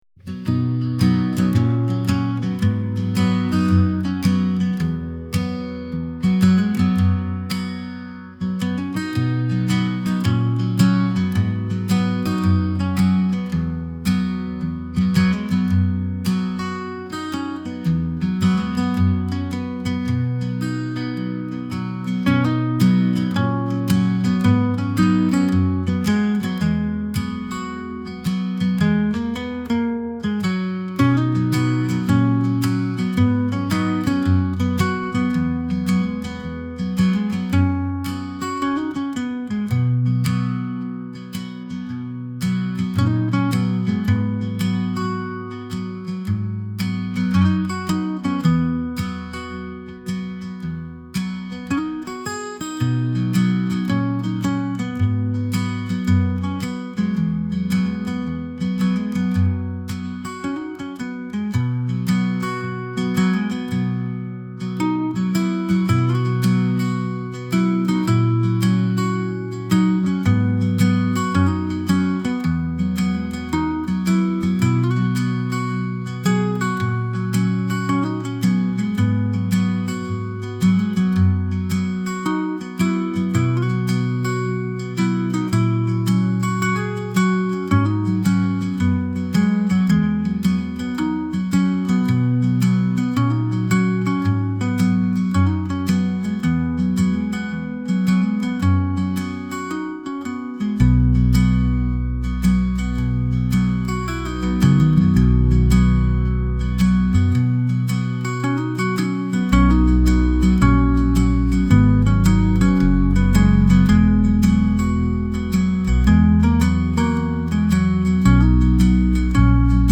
どこか懐かしい夏 ギター
穏やか